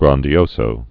(grändē-ōsō, -zō, grăn-)